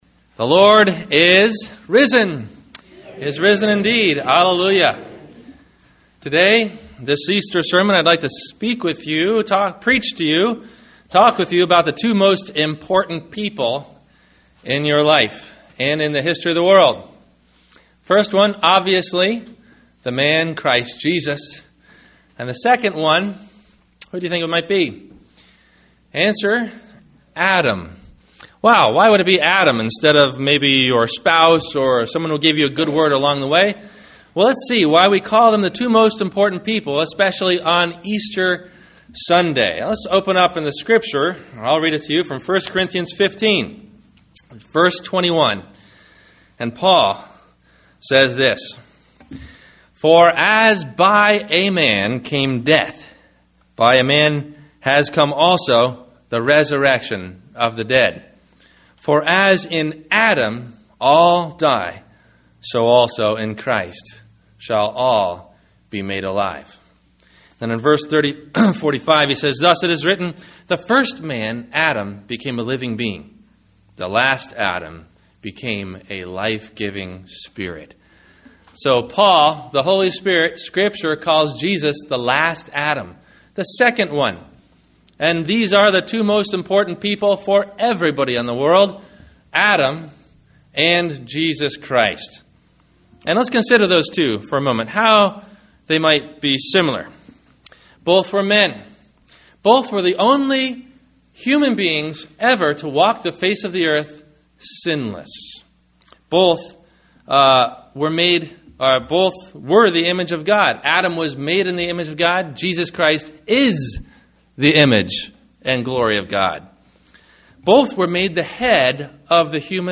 What Happens When We Die? – Sermon – April 07 2013